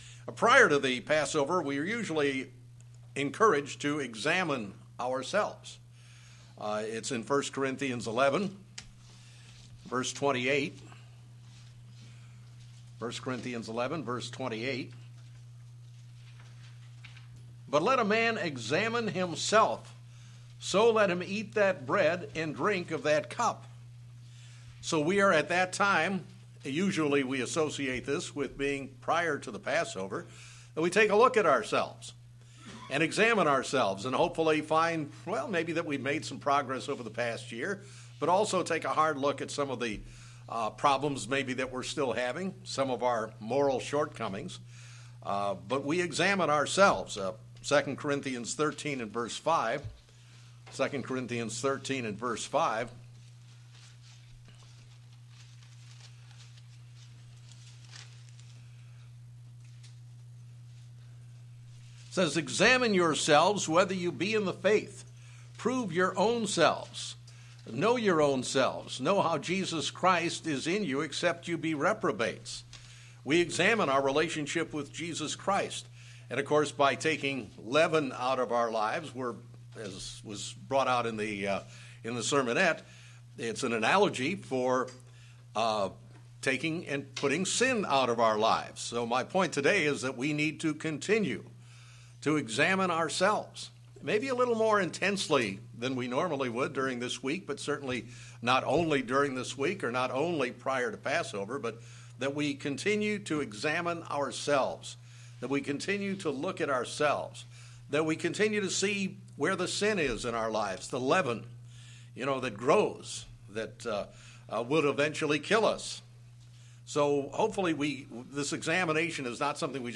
In the months leading up to Passover we find ourselves going through a period of self examination. This sermon will take a look at the process of examining our lives throughout the year.